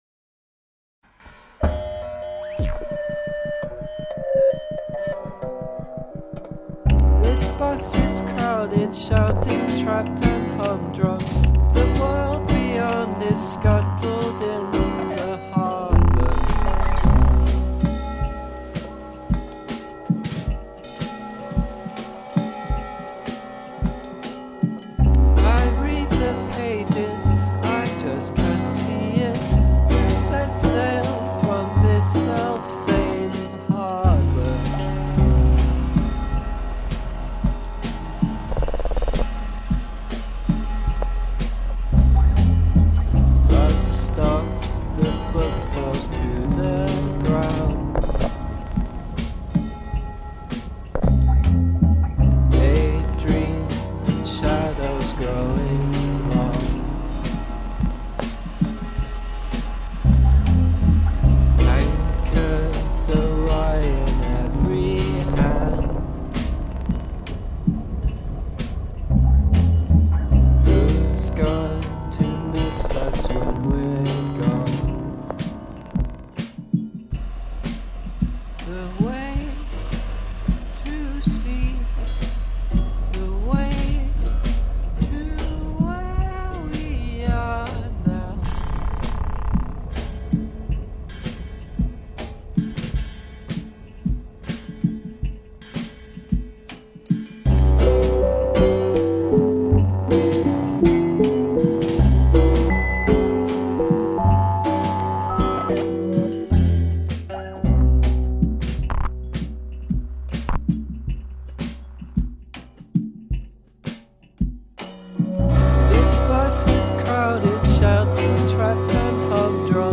Because they like inventive home recording™.